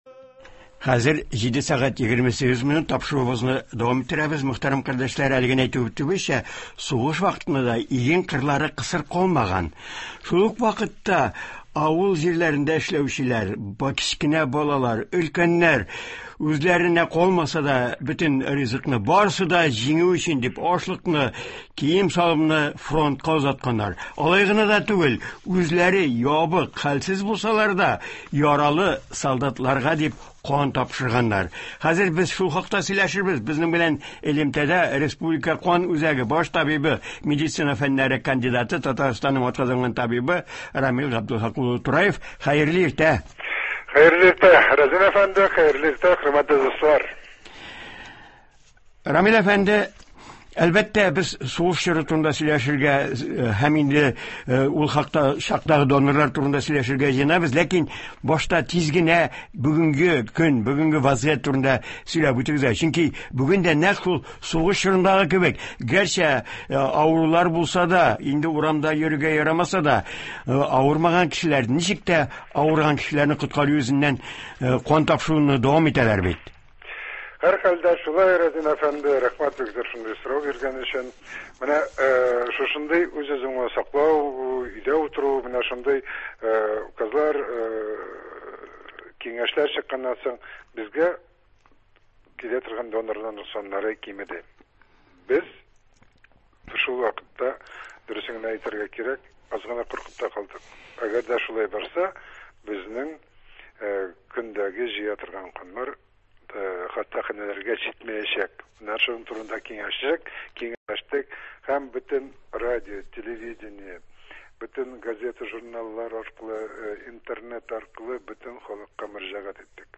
Туры эфир. 22 апрель.